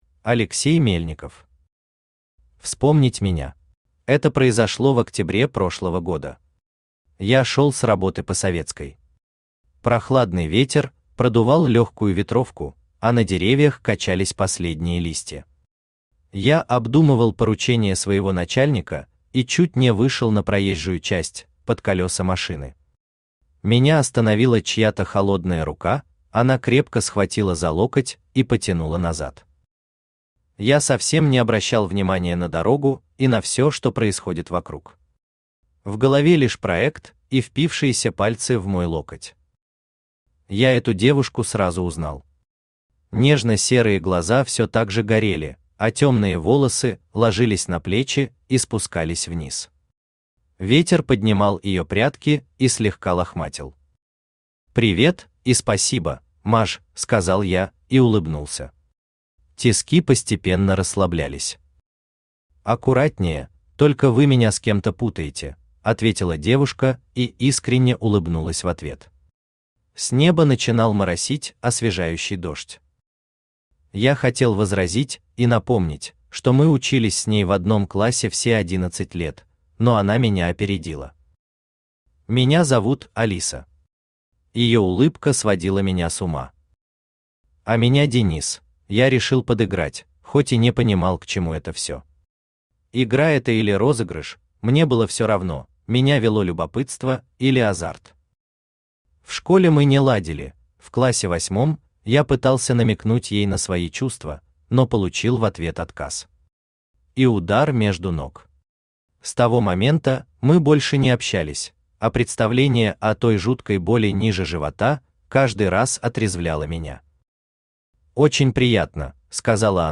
Аудиокнига Вспомнить меня | Библиотека аудиокниг
Aудиокнига Вспомнить меня Автор Алексей Романович Мельников Читает аудиокнигу Авточтец ЛитРес.